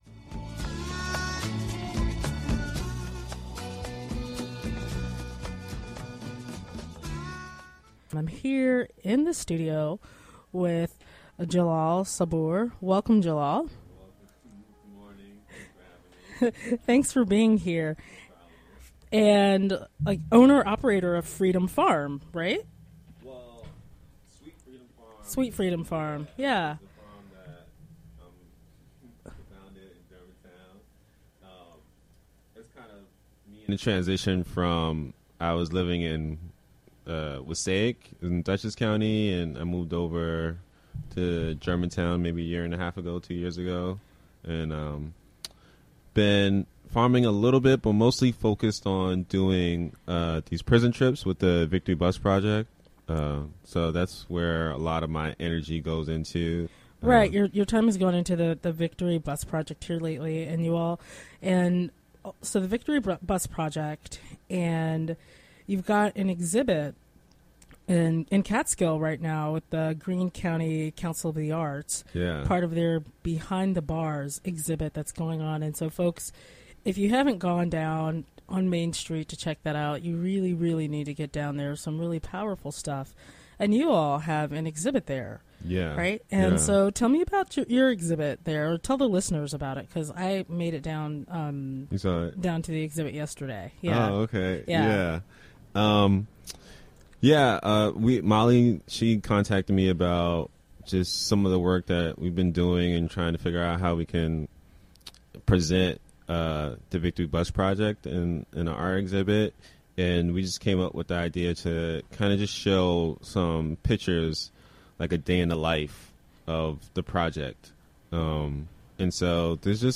Interview was conducted during the WGXC Morning Show.